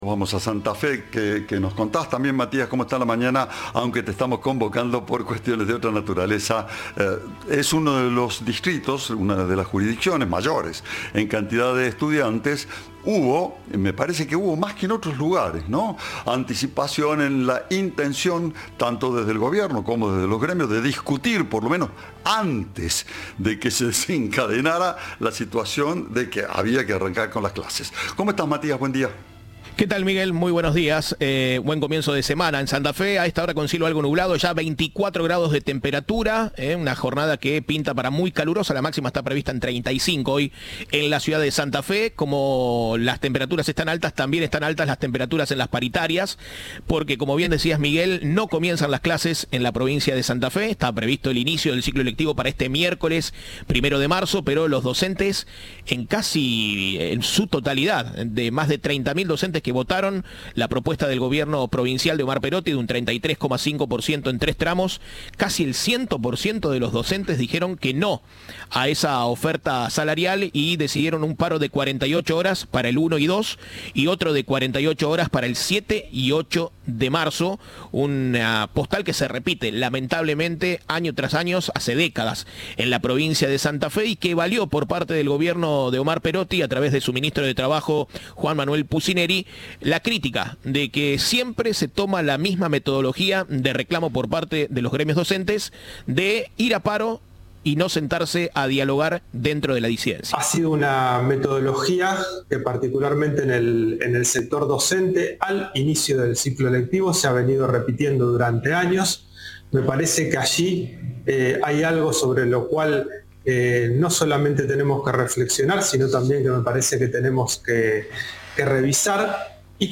El ministro de Trabajo, Empleo y Seguridad Social de Santa Fe, Juan Manuel Pusineri, se refirió al rechazo del sector docente santafesino a la propuesta salarial realizada por el gobierno provincial.